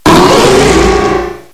cry_not_mega_ampharos.aif